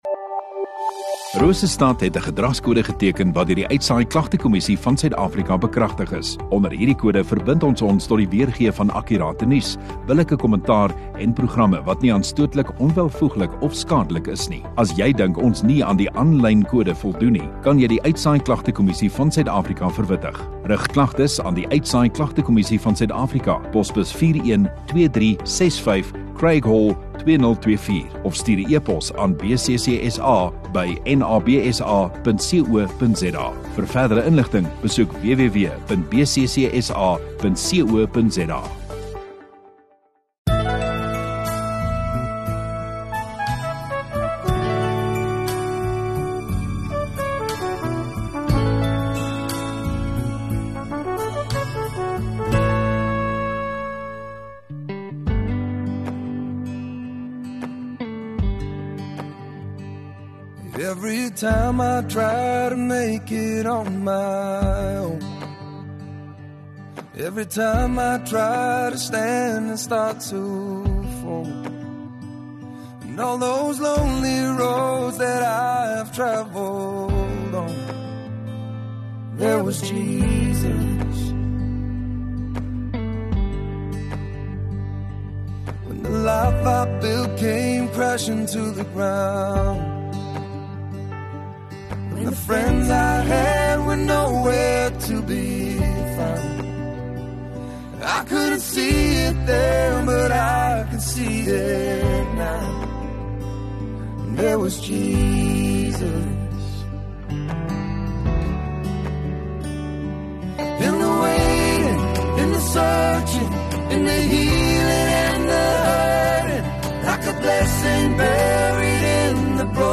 25 May Saterdag Oggenddiens